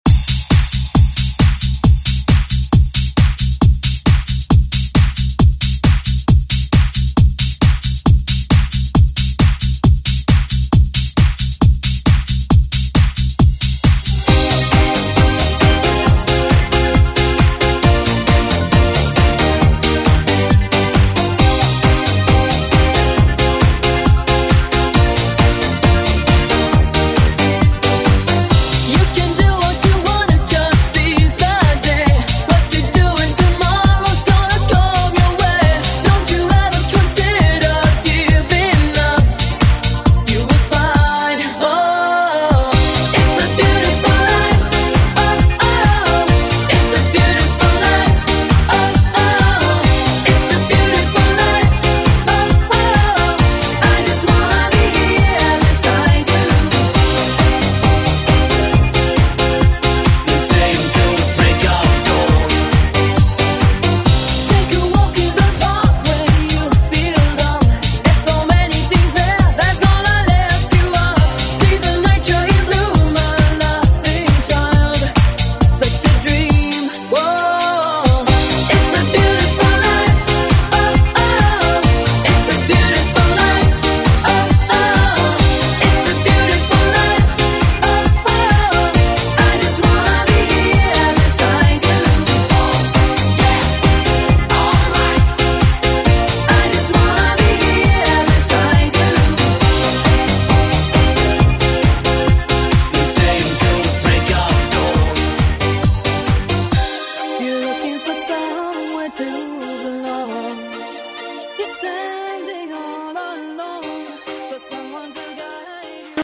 Genre: EDM
Dirty BPM: 137 Time